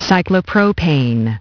Transcription and pronunciation of the word "cyclopropane" in British and American variants.